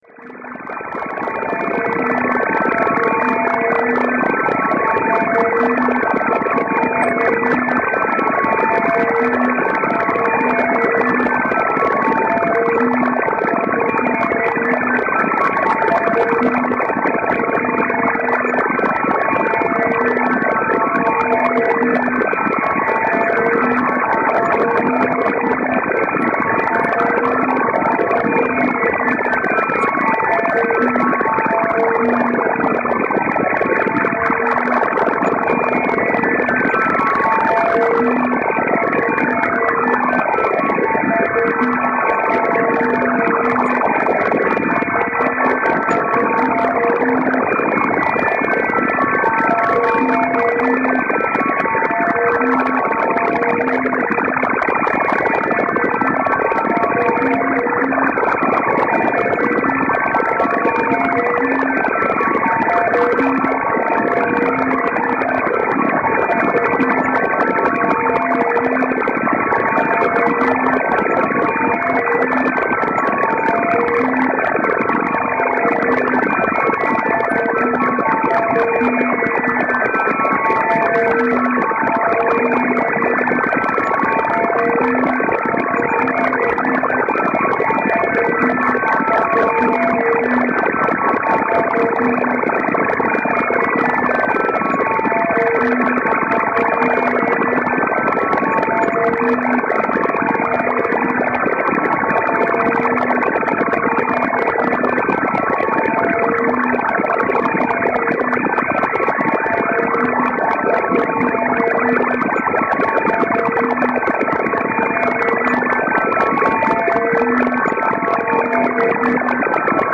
描述：各种航天器声音/升空/降落/激光
Tag: 外太空 SF 空间船舶 外来